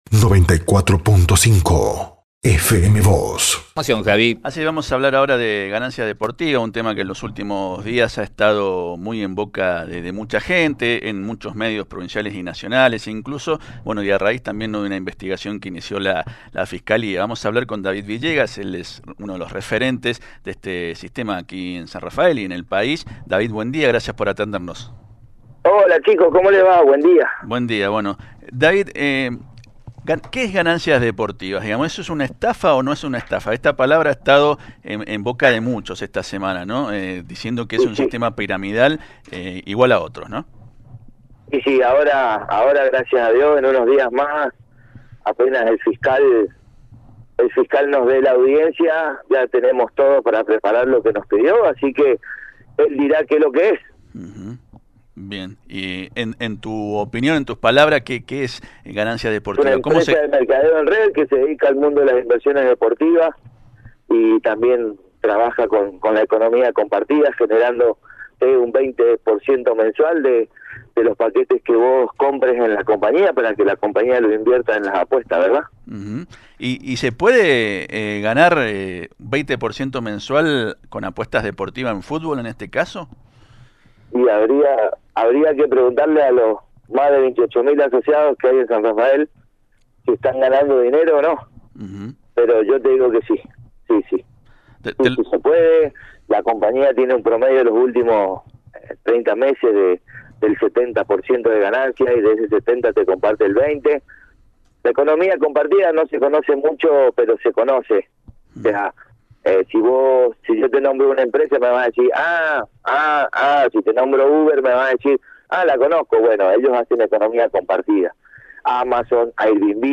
habló con FM Vos (94.5) y Diario San Rafael.